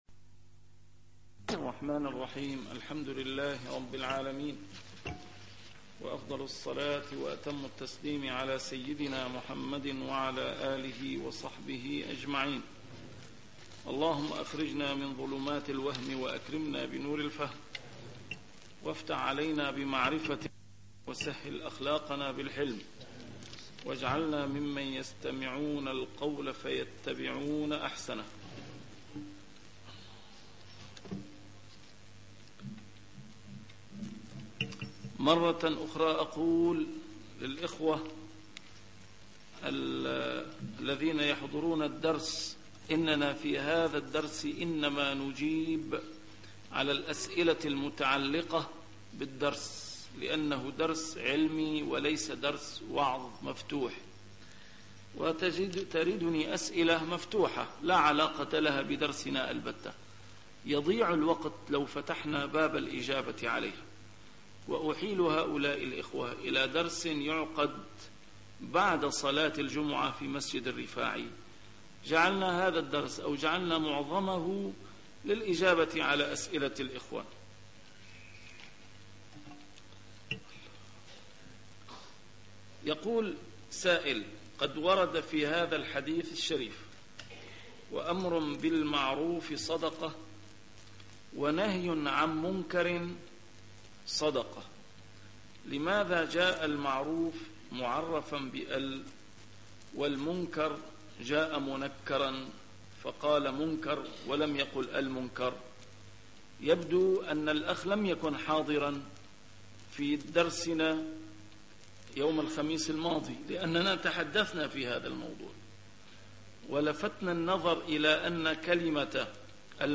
A MARTYR SCHOLAR: IMAM MUHAMMAD SAEED RAMADAN AL-BOUTI - الدروس العلمية - شرح الأحاديث الأربعين النووية - تتمة شرح الحديث الخامس والعشرون: حديث أبي ذر الغفاري (ذهب أهل الدثور بالأجور) 85